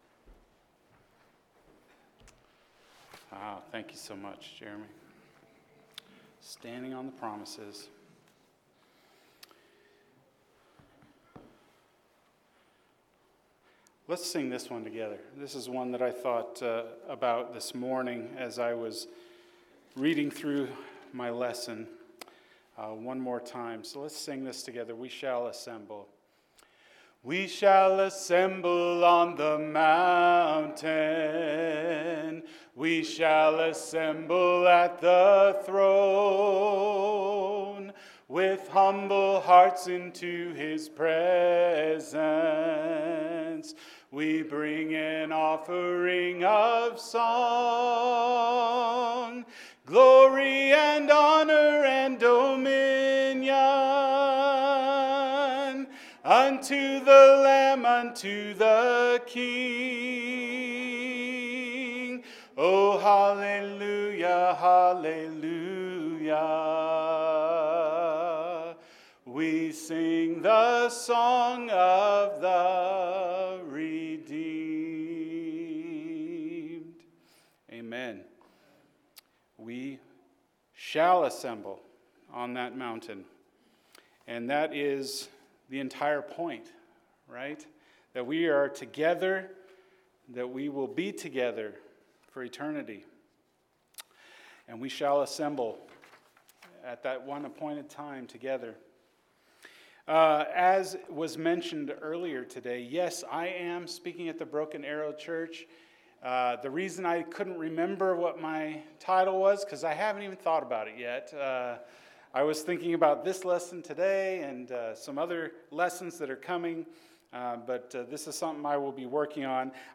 Reconciliation – Acts 1:12-20 – Sermon